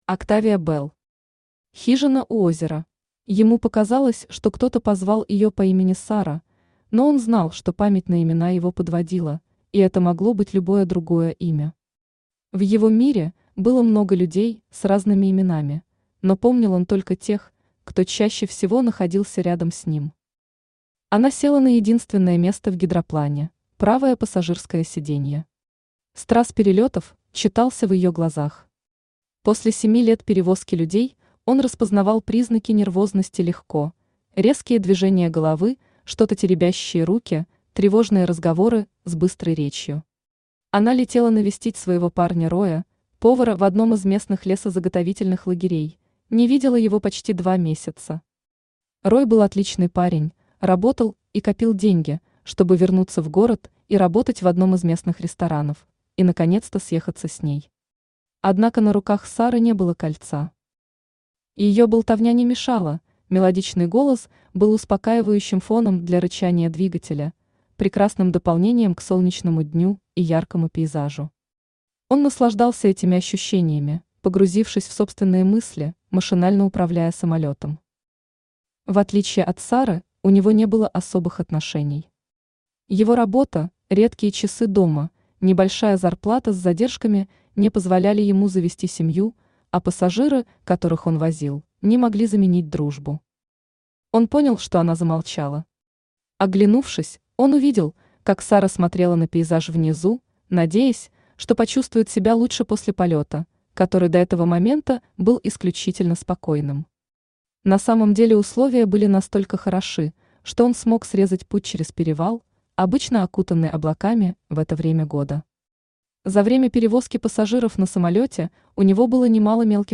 Аудиокнига Хижина у озера | Библиотека аудиокниг
Aудиокнига Хижина у озера Автор Октавия Белл Читает аудиокнигу Авточтец ЛитРес.